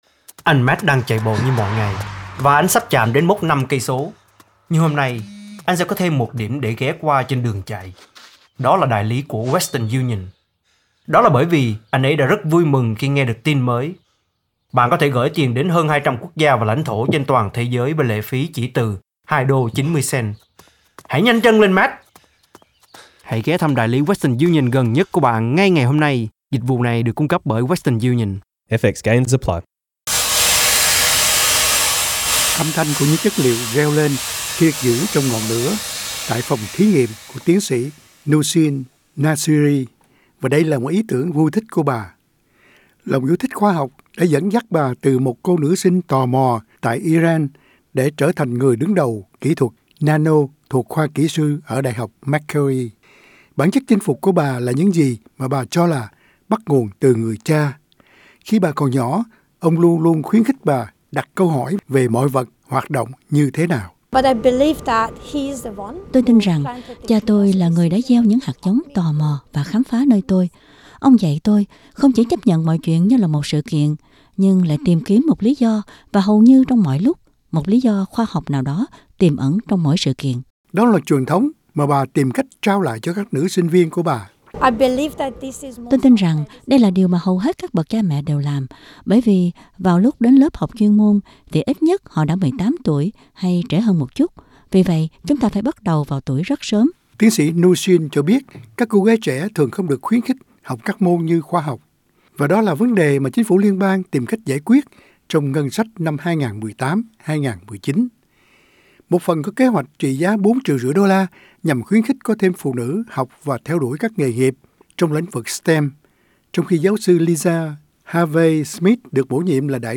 Âm thanh của những chất liệu reo lên khi được giữ trong ngọn lửa